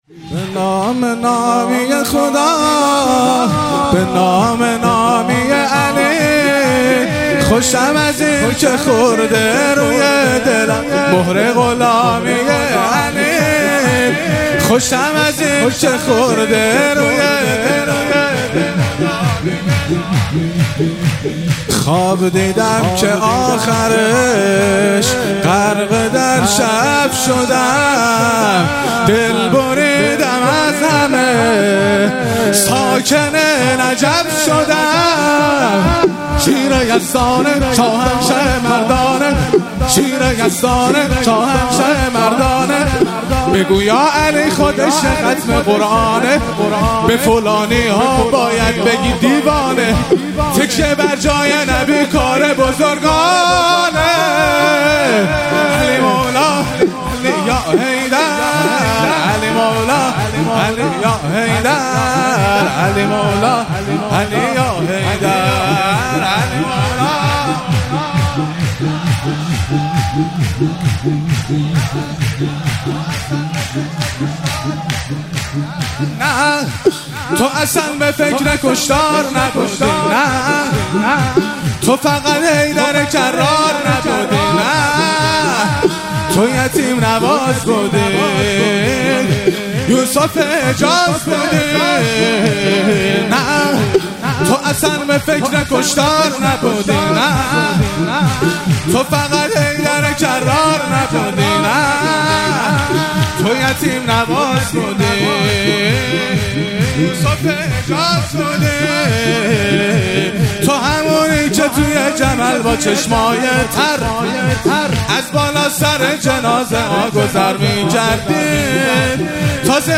مراسم مناجات خوانی شب پانزدهم و جشن ولادت امام حسن مجتبی علیه السلام ماه رمضان 1444